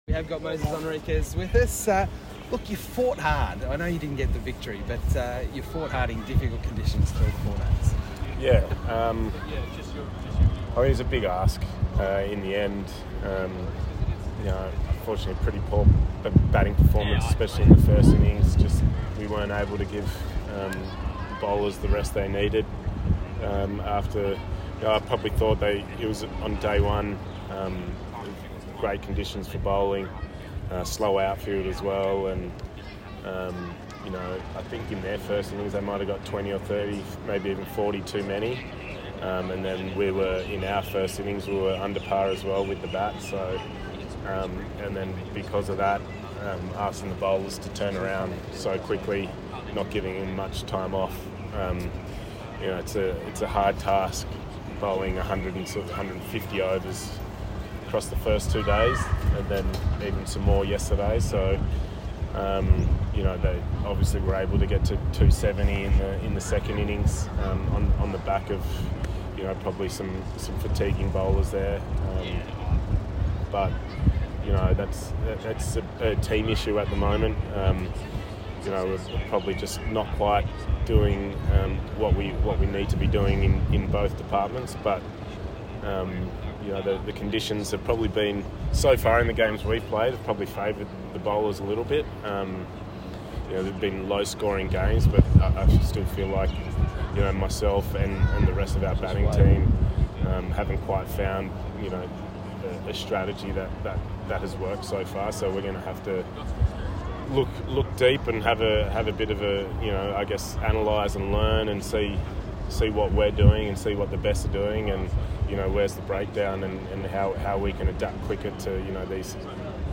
New South Wales captain Moises Henriques speaks following NSW loss to Victoria